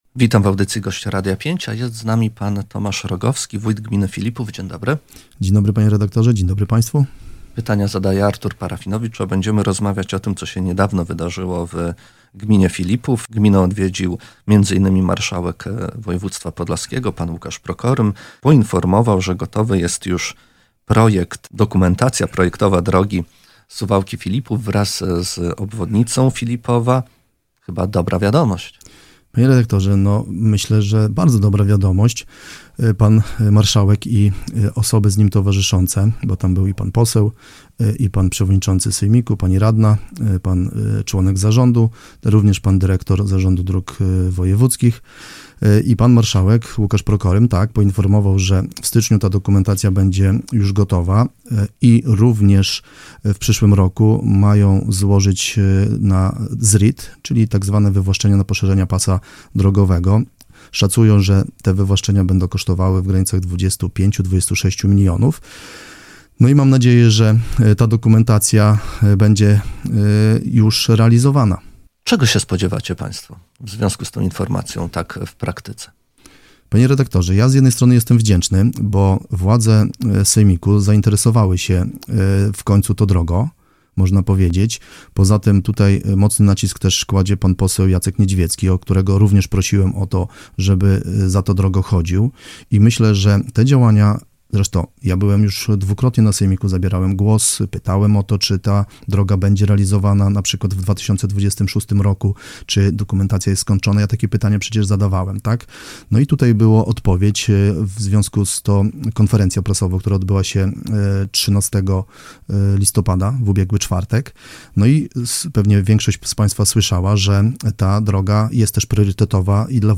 Z ostrożnym optymizmem przyjęli informacje o przygotowaniach do budowy drogi do Suwałk mieszkańcy gminy Filipów. Tak przynajmniej wynika z wypowiedzi Tomasza Rogowskiego, wójta gminy, który gościł dziś w Radiu 5.